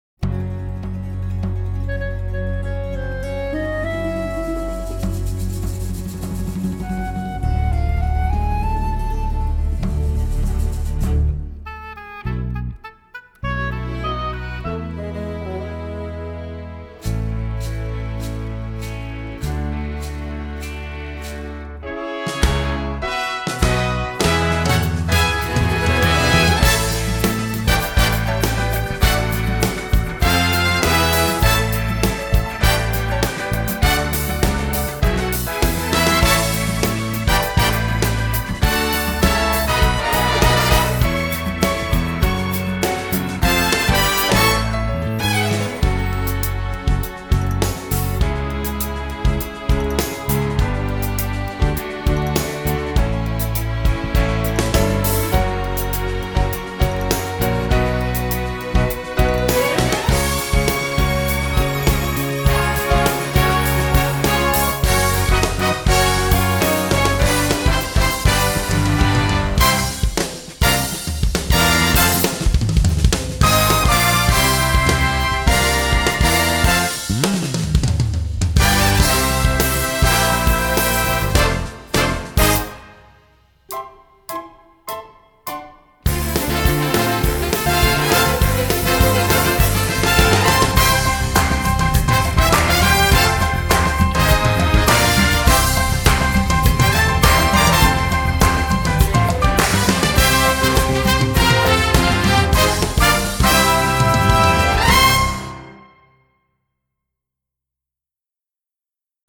Song with lyrics